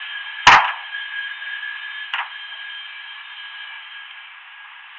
Earthquakes
Here are a couple of examples of how earthquakes signals can sound.
The sounds you hear represent the sudden release of acoustic/seismic energy. Some people say it sounds like a slamming door.